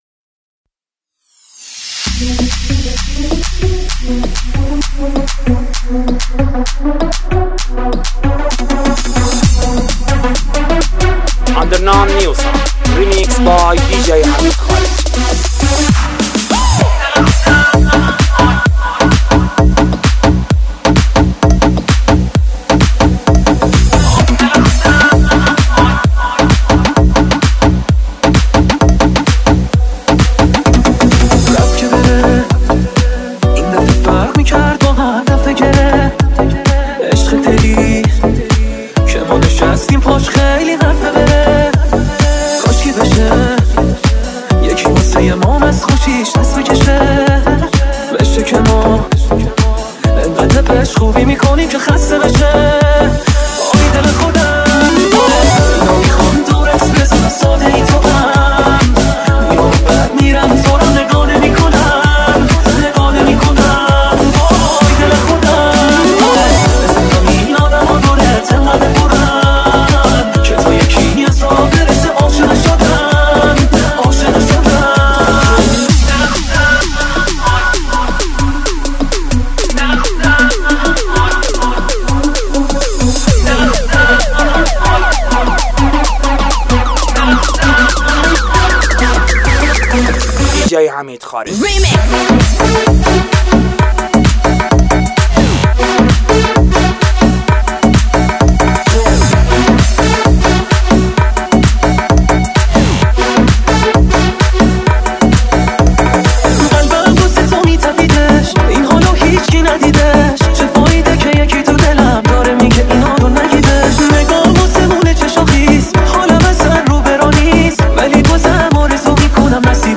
این میکس احساسی و جذاب